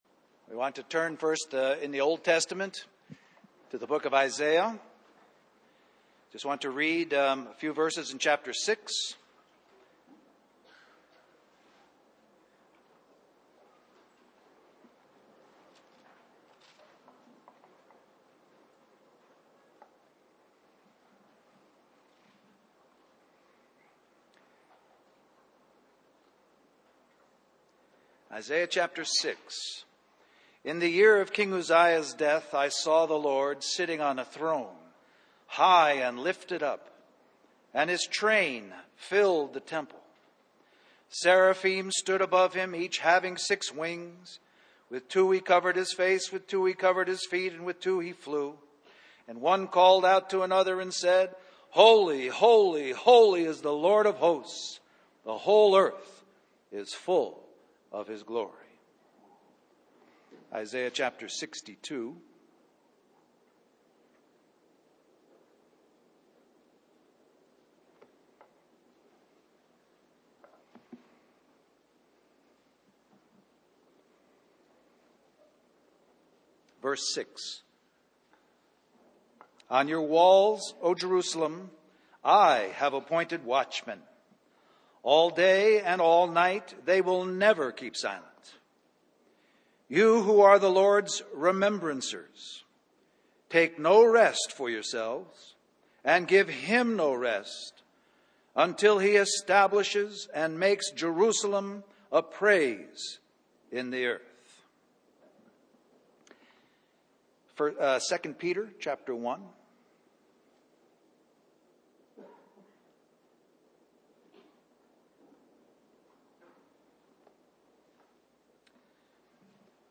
Christian Family Conference We apologize for the poor quality audio